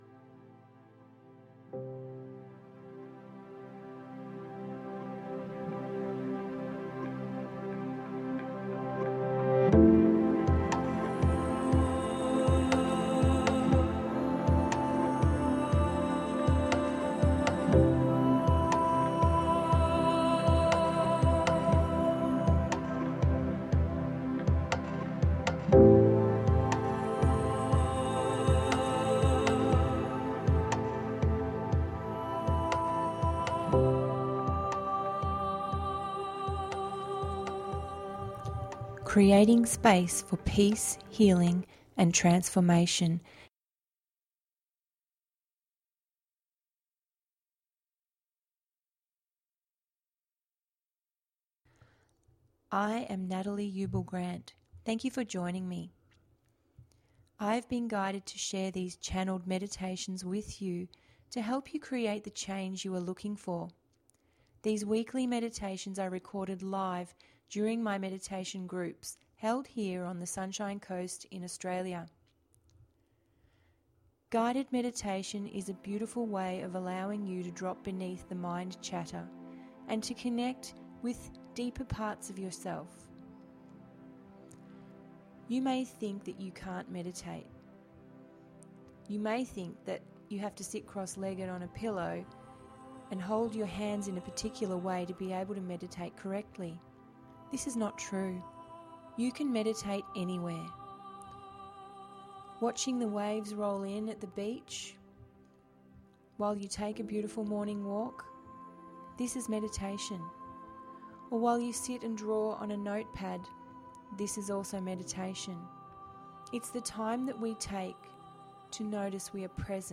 In this deeply soothing guided meditation we connect with our Tree Of Creation and feel the energy present. Allow the energy of the tree to cleanse and clear any negative energy and replace it with healing love and be filled with light.